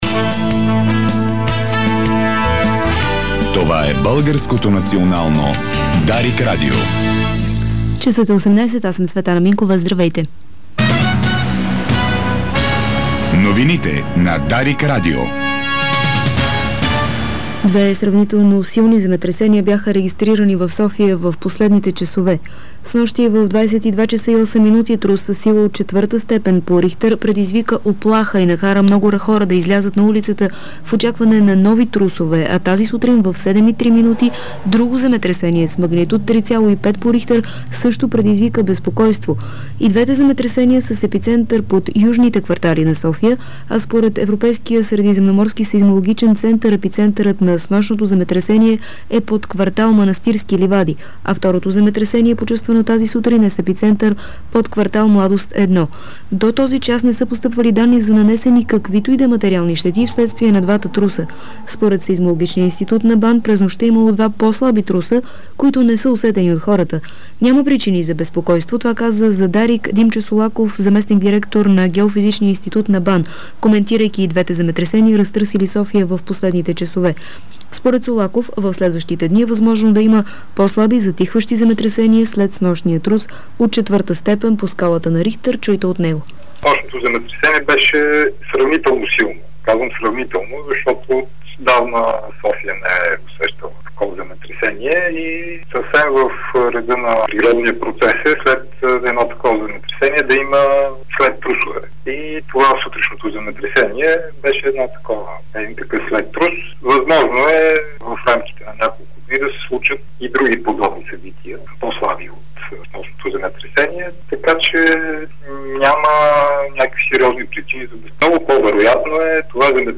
Обзорна информационна емисия - 16.11.2008